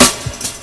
Snare (7).wav